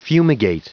Prononciation du mot fumigate en anglais (fichier audio)
Prononciation du mot : fumigate
fumigate.wav